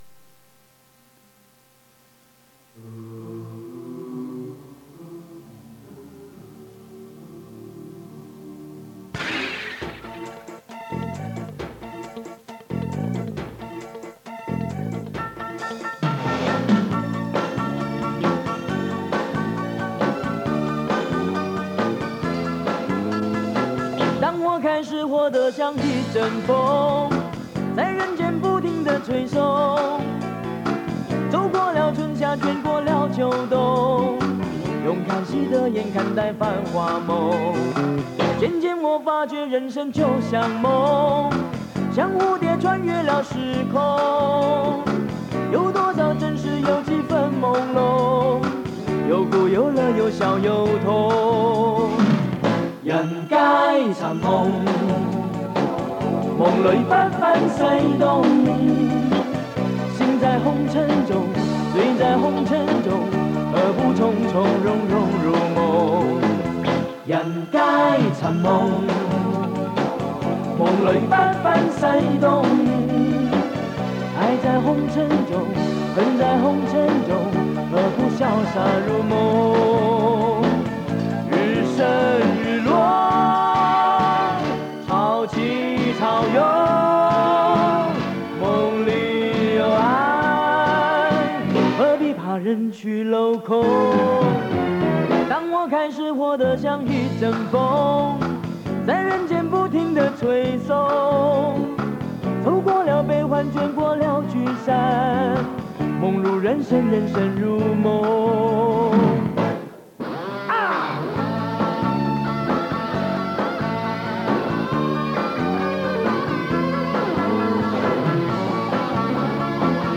磁带数字化：2022-07-16